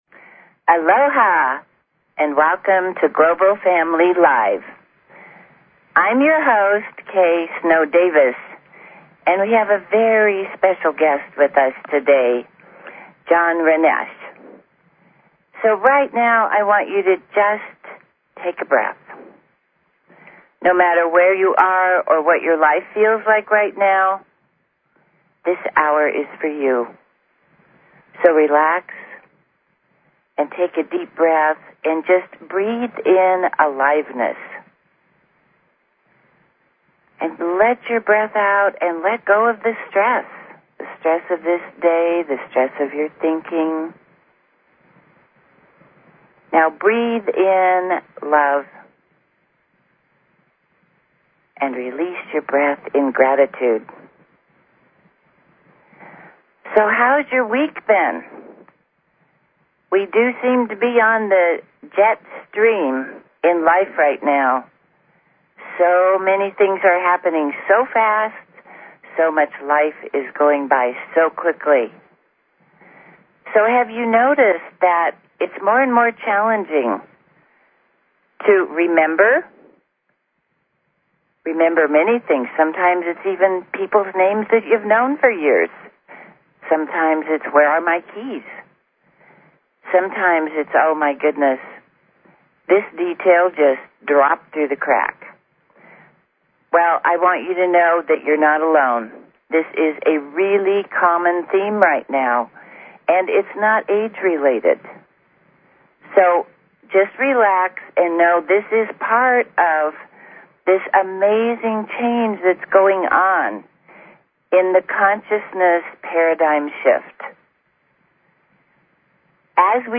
Talk Show Episode, Audio Podcast, Global_Family_Live and Courtesy of BBS Radio on , show guests , about , categorized as